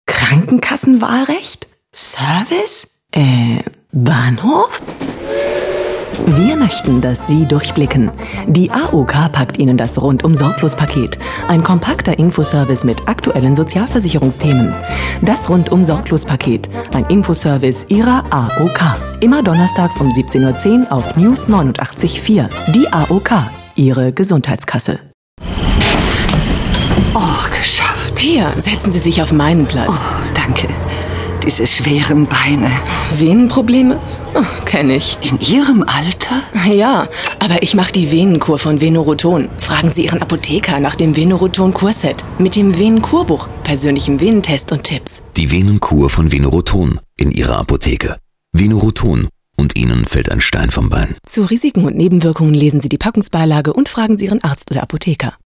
Profi-Sprecherin deutsch, Werbesprecherin.
Kein Dialekt
Sprechprobe: Werbung (Muttersprache):
female voice over artist german.